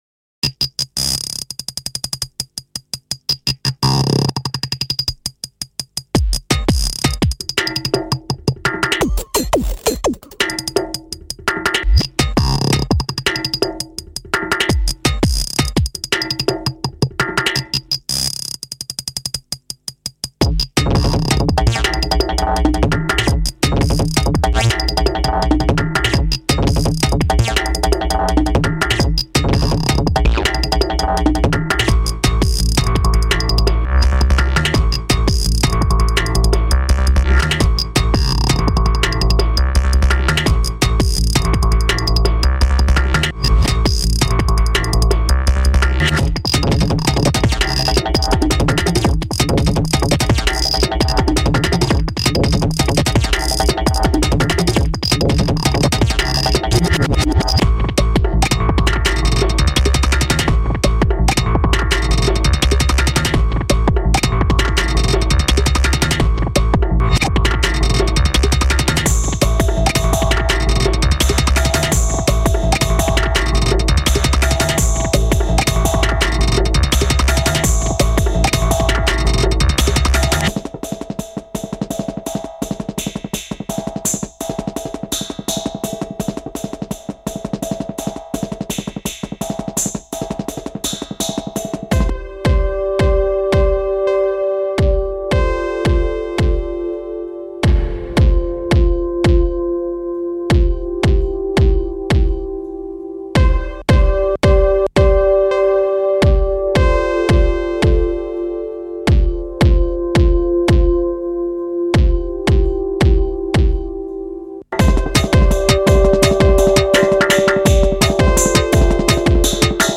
frantic yet graceful